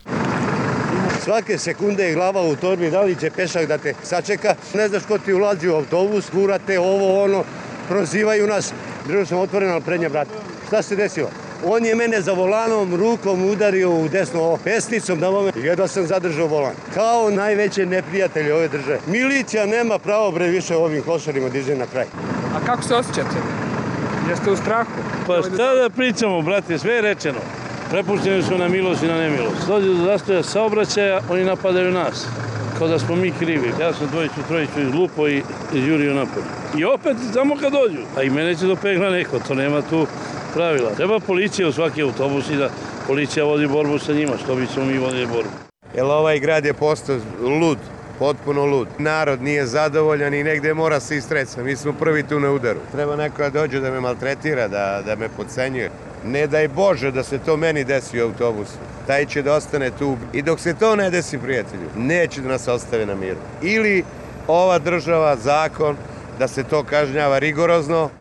Vozači